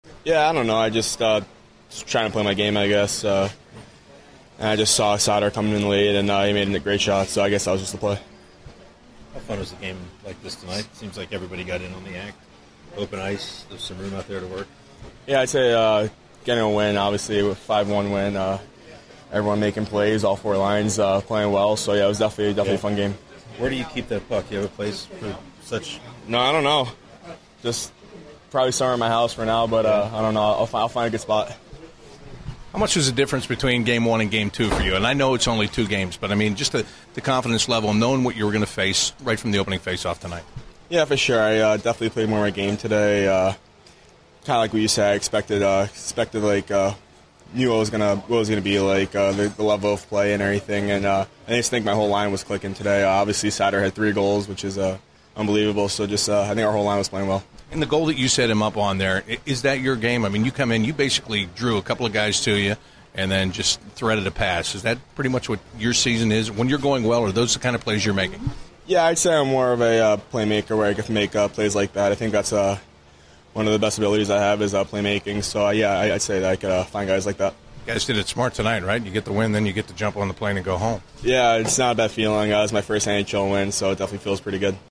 An episode by CBJ Interviews